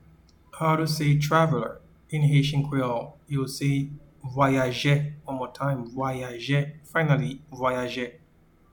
Pronunciation:
Traveler-in-Haitian-Creole-Vwayaje.mp3